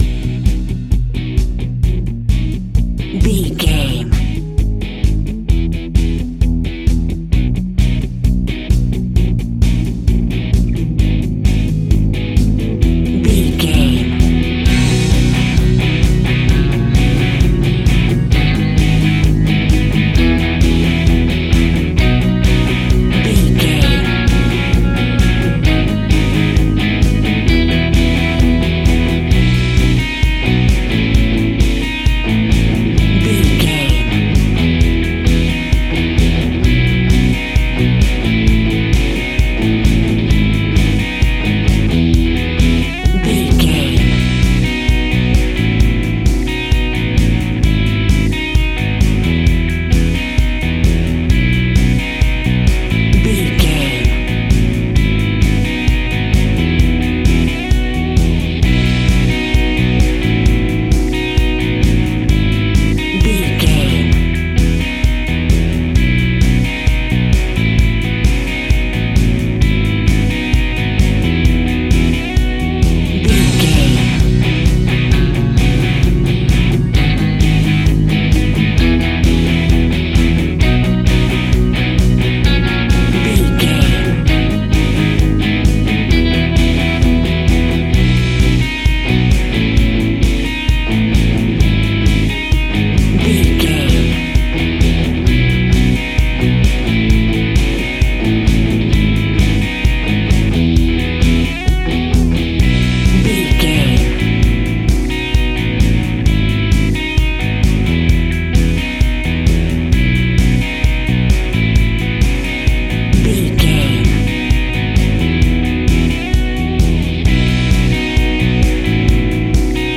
Modern Indie Top 40 Rock.
Ionian/Major
pop rock
indie pop
fun
energetic
uplifting
electric guitar
Distorted Guitar
Rock Bass
Rock Drums
hammond organ